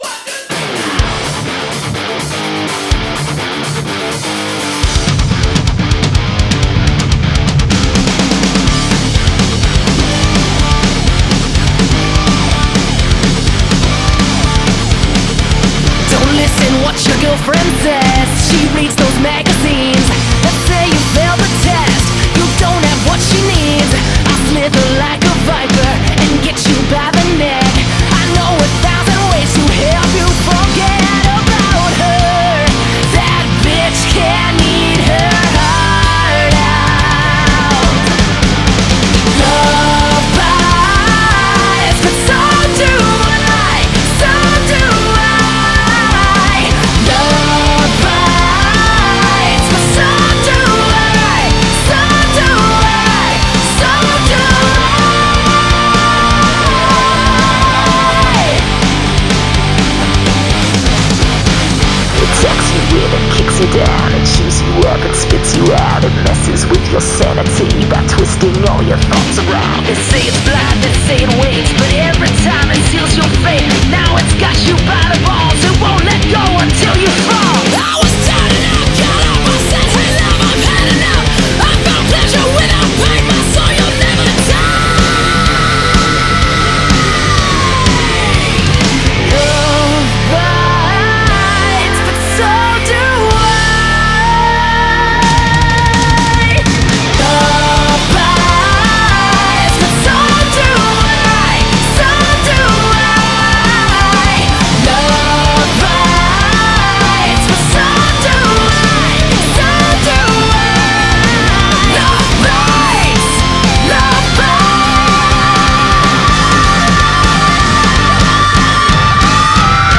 BPM250
Audio QualityMusic Cut
Everything else is a constant 250.